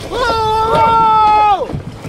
Bruitages cris d'hommes 2